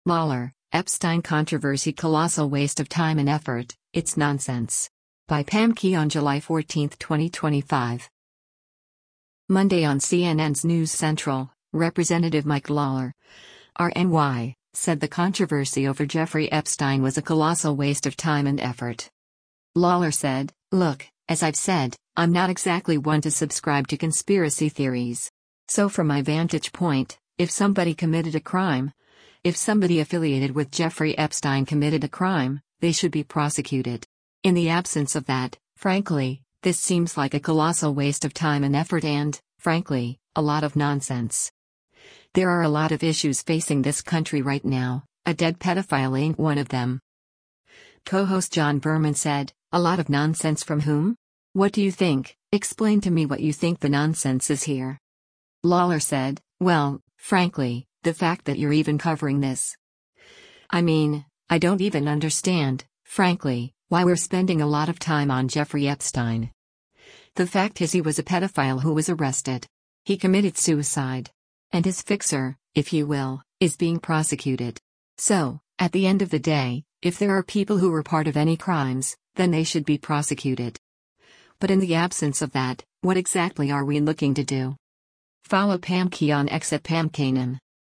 Monday on CNN’s “News Central,” Rep. Mike Lawler (R-NY) said the controversy over Jeffrey Epstein was a “colossal waste of time and effort.”